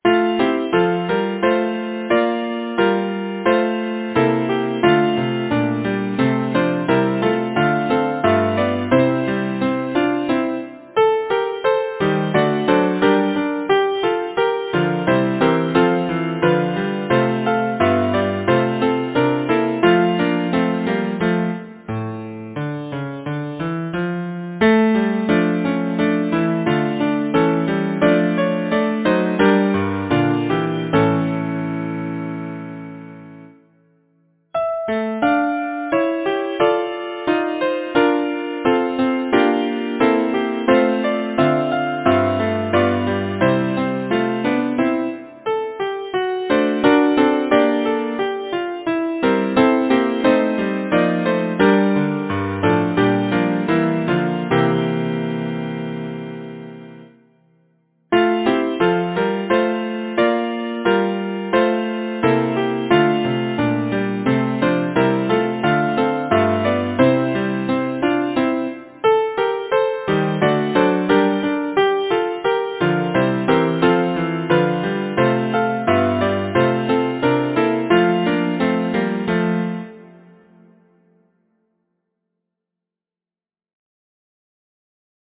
Title: When Flora decks Composer: William Noel Johnson Lyricist: John Cunningham Number of voices: 4vv Voicing: SATB Genre: Secular, Partsong
Language: English Instruments: A cappella